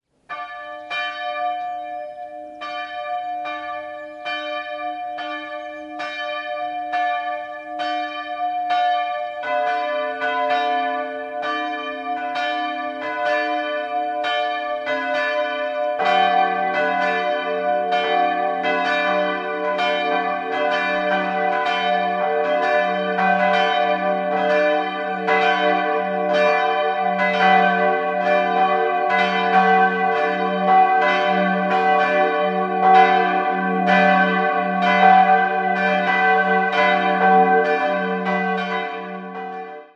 Die große ist unbezeichnet und wurde evtl. in Nürnberg gegossen, die mittlere stammt von Friedrich Böheim (Amberg) aus dem Jahr 1670 und die kleine wurde 1732 von Magnus Gabriel Reinburg gegossen.